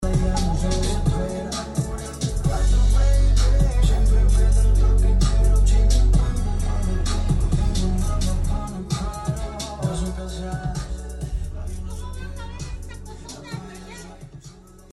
Chuponcito car audio sound effects free download